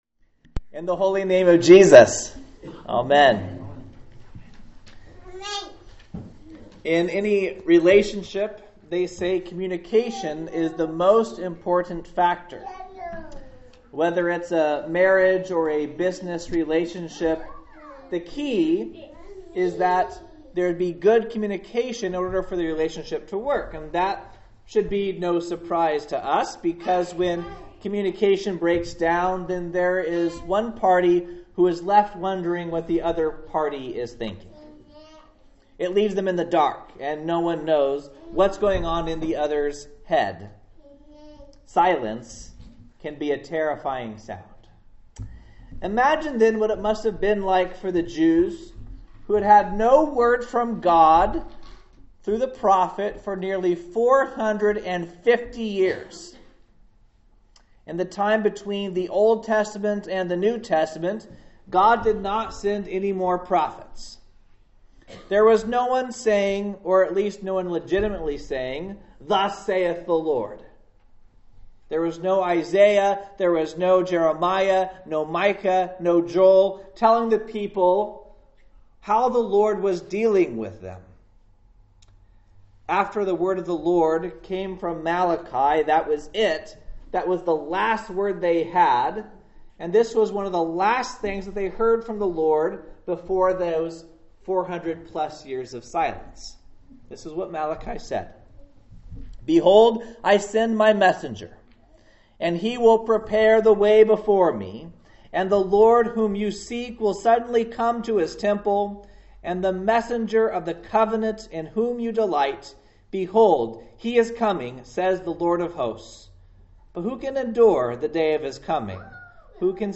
Sermons – Faith Lutheran Church - Page 6